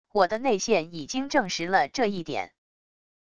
我的内线已经证实了这一点wav音频生成系统WAV Audio Player